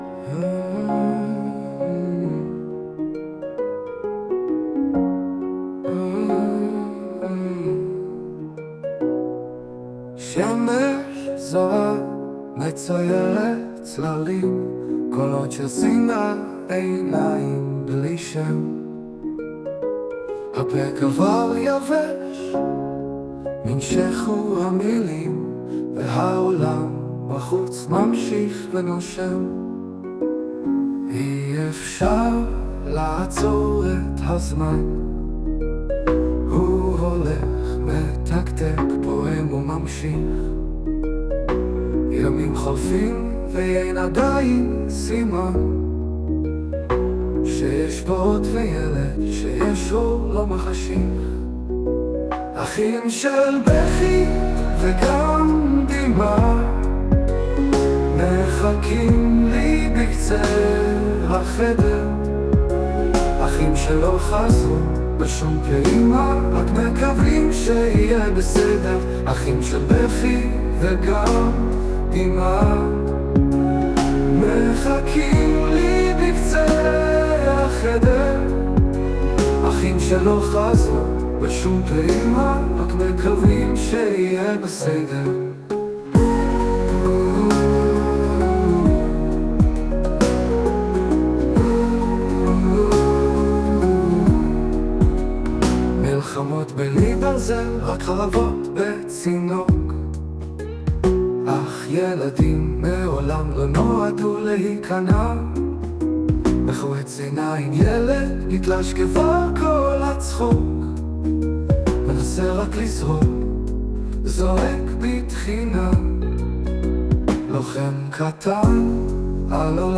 ישנם חסרונות רבים היכולים להיגרם ע"י שירת רובוט.
לפעמים השיר די בסדר, העיבוד יפה. אבל הפזמון? יבש. לא מתקדם לשום מקום. וגם אין קשר בין ההתחלה לאמצע והסוף.